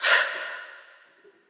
ShockedBreathe.wav